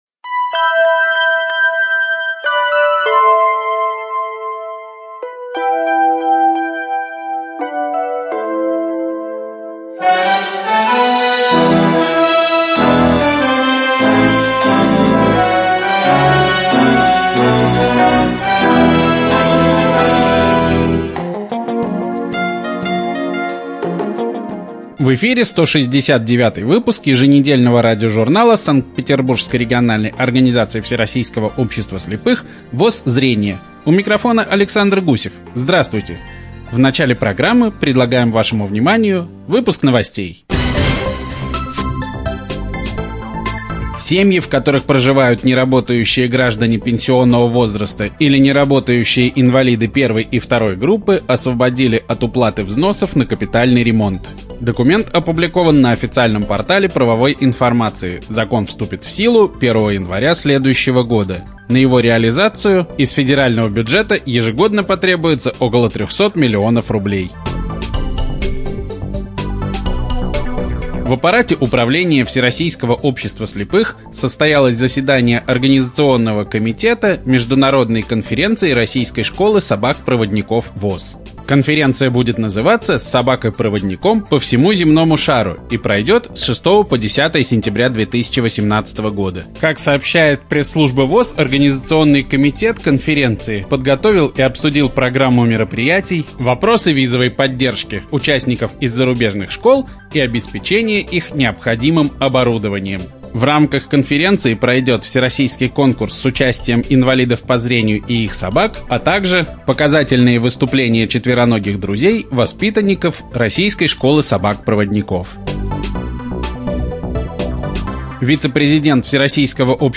ЖанрРеабилитация, Радиопрограммы